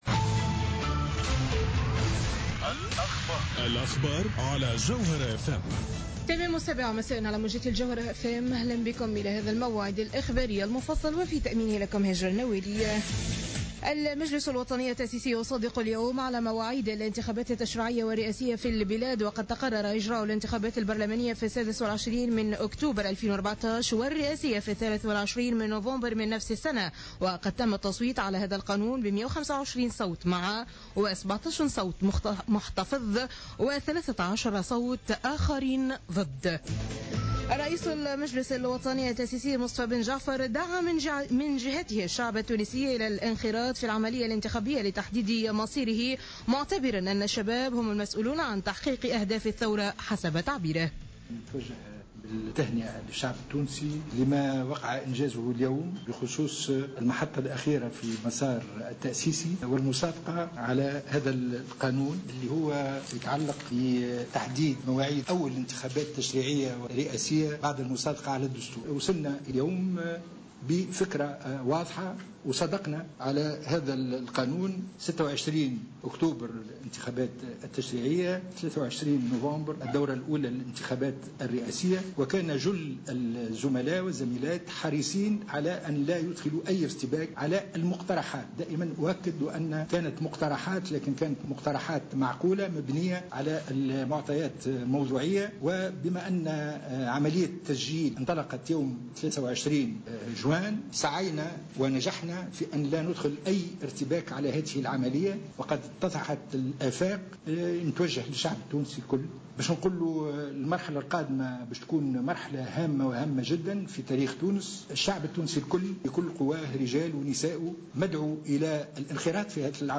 نشرة أخبار السابعة مساء ليوم الأربعاء 25-06-14